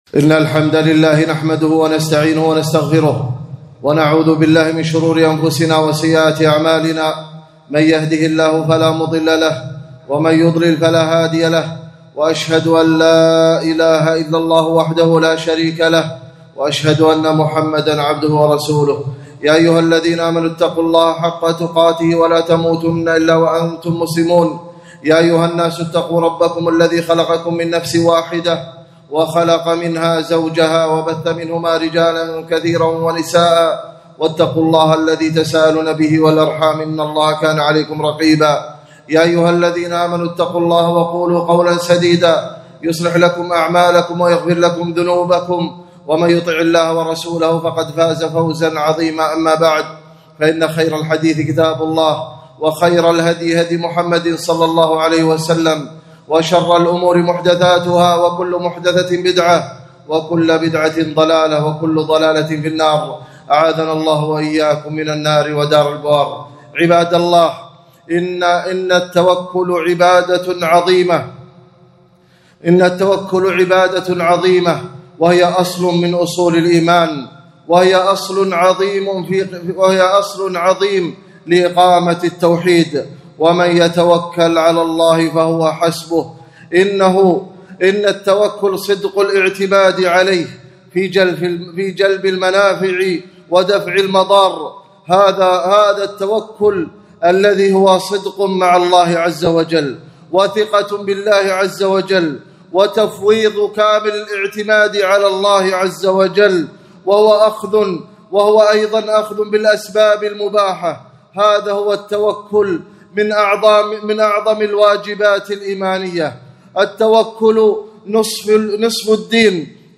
خطبة - أعقلها وتوكل